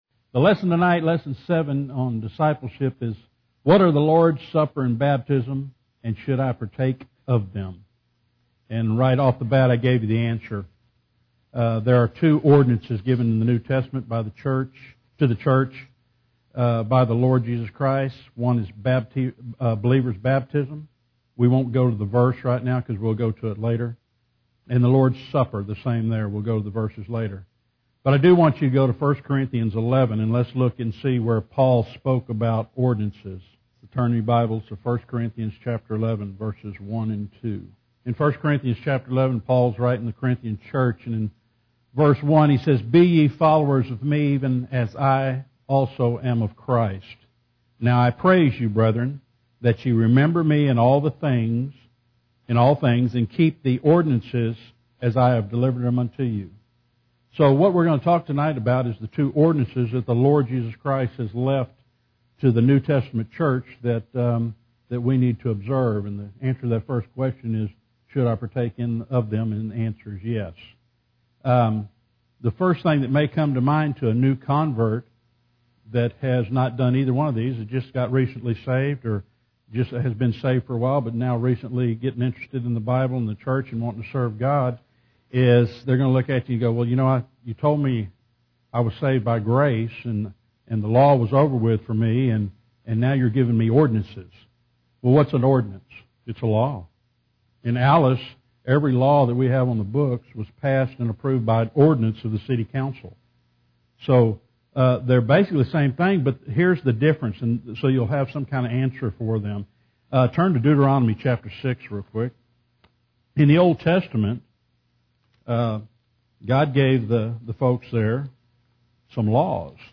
Discipleship Lesson #7- The Lord's Supper & Baptism - Bible Believers Baptist Church | Corpus Christi, Texas